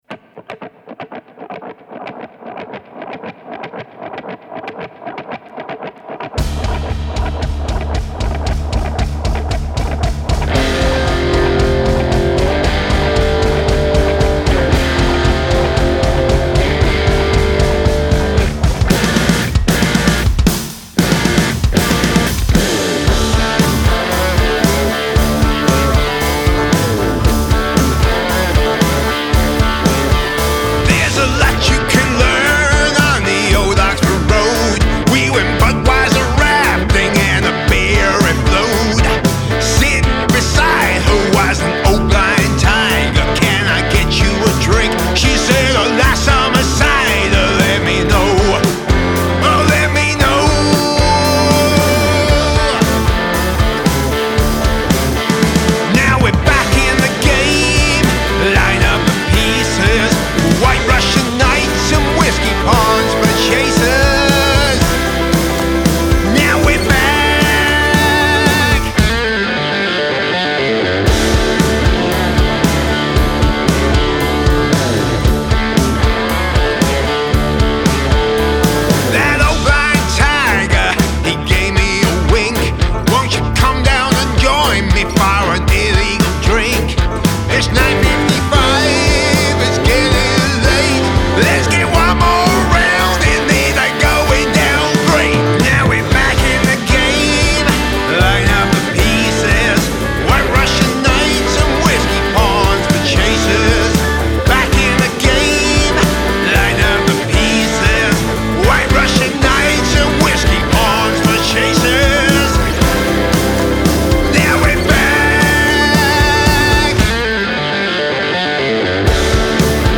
Male Vocal, Guitar, Banjo, Bass Guitar, Drums